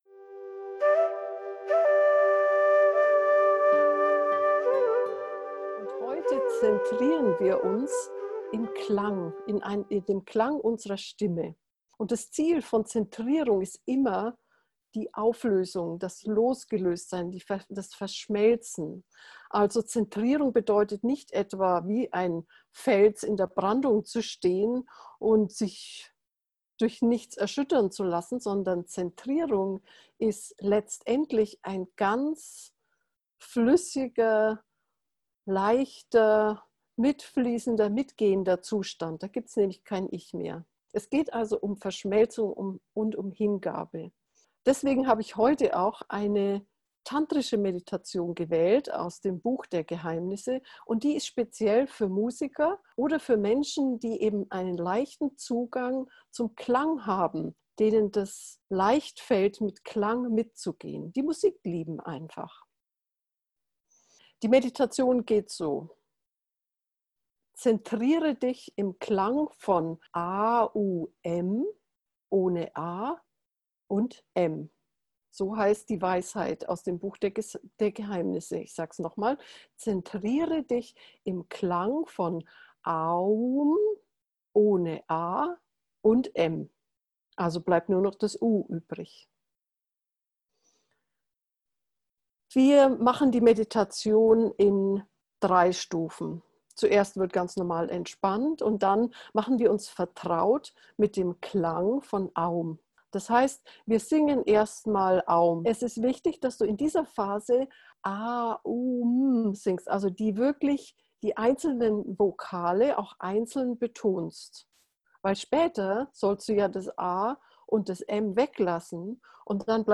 Folge 27: A-U-M – Geführte Meditation für Musiker und sensible Menschen; Zentrierung im Klang von U - FindYourNose
AUM-gefuehrte-Meditation-Musiker-hochsensible.mp3